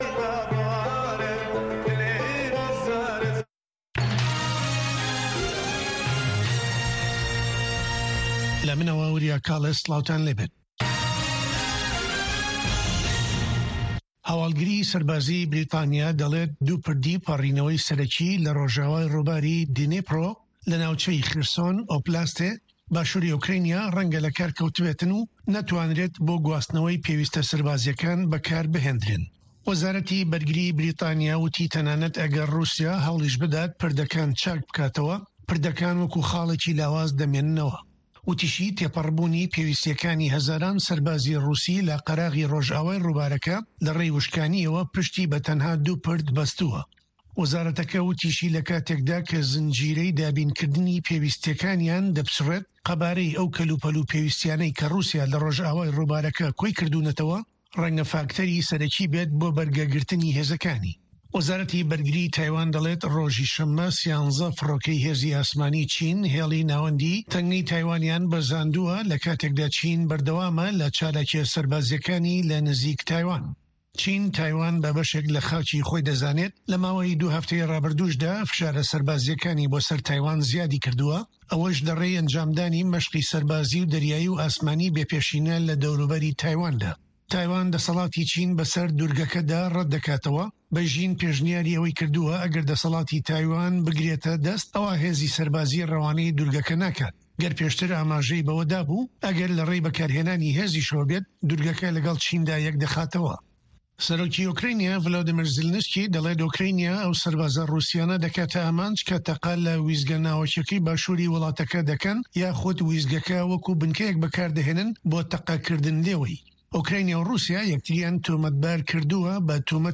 هەواڵە جیهانییەکان 2
هەواڵە جیهانیـیەکان لە دەنگی ئەمەریکا